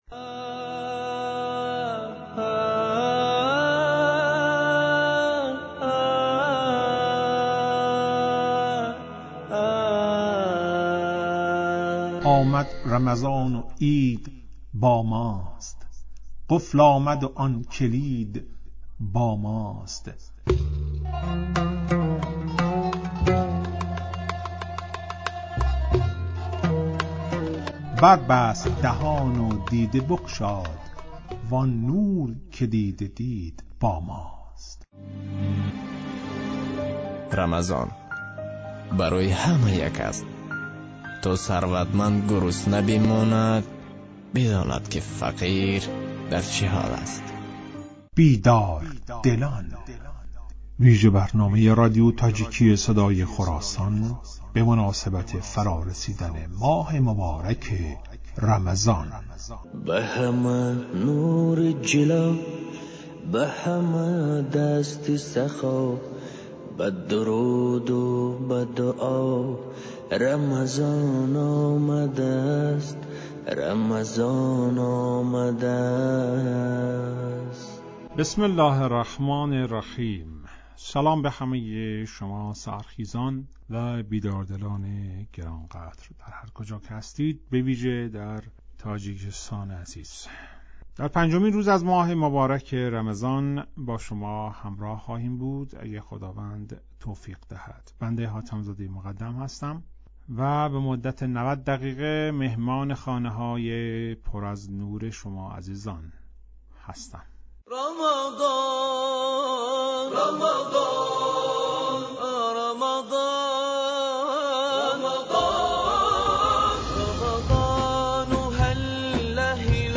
"بیدار دلان" ویژه برنامه ای است که به مناسبت ایام ماه مبارک رمضان در رادیو تاجیکی تهیه و پخش می شود.